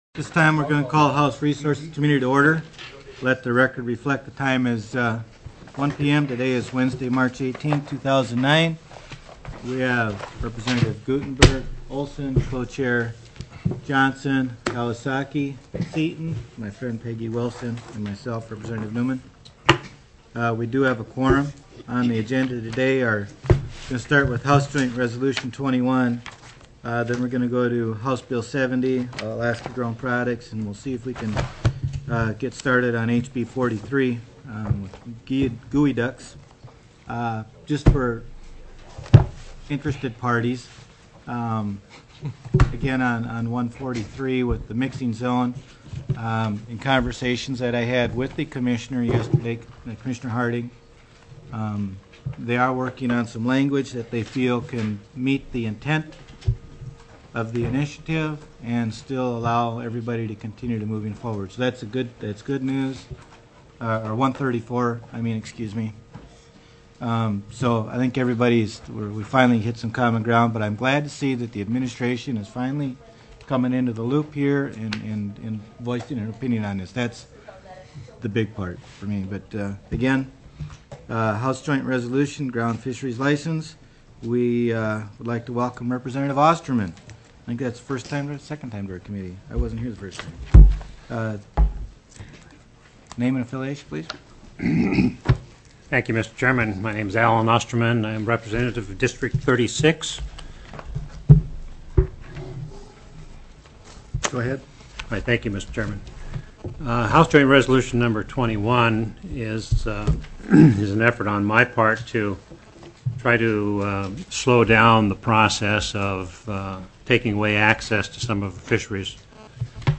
03/18/2009 01:00 PM House RESOURCES
HJR 21 GROUNDFISH FISHERIES LICENSES TELECONFERENCED